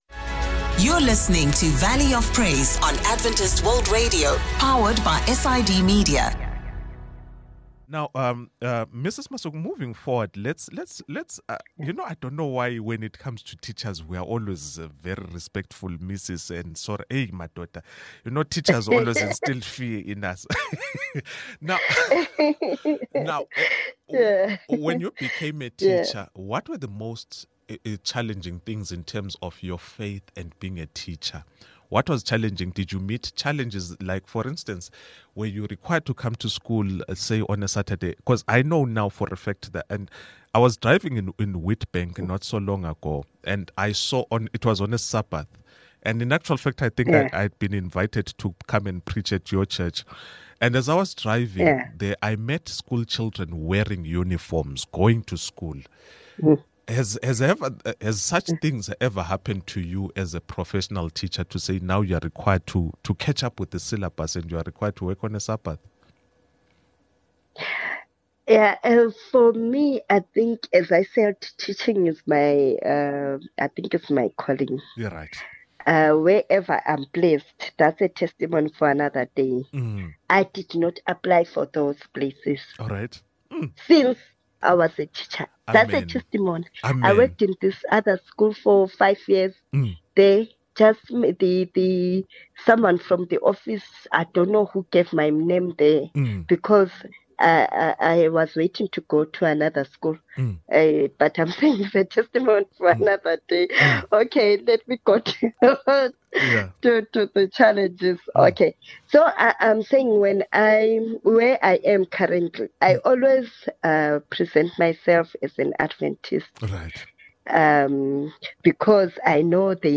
On this episode of Faith Meets Profession, we sit down with a passionate teacher who has dedicated her career to shaping the future of young minds. She shares her journey of balancing her professional life with her Christian faith, offering insights on how her beliefs guide her decisions in the classroom. From overcoming challenges to celebrating victories, this conversation explores the powerful intersection of education and faith.